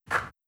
xrjamfeb2025/assets/freesound/518048_swish_edited.wav at f2eee081f26f2bf5061bdfc63cd6b4afdd2bf149